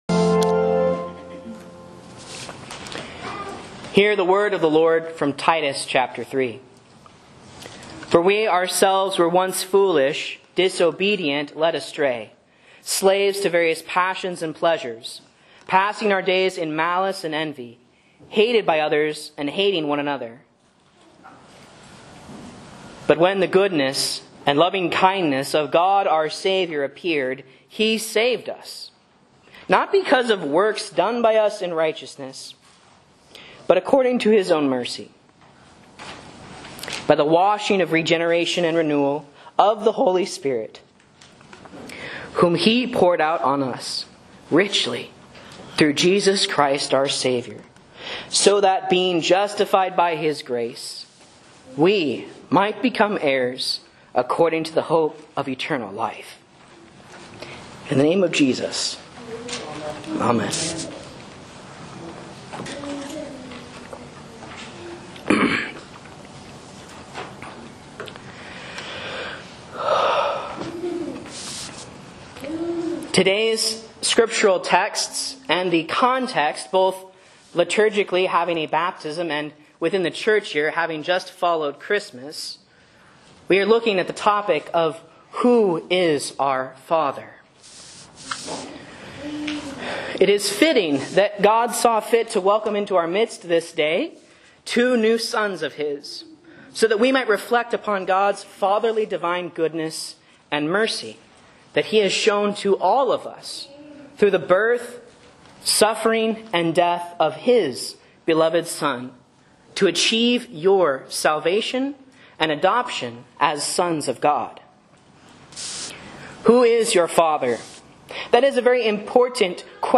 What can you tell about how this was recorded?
Sermons and Lessons from Faith Lutheran Church, Rogue River, OR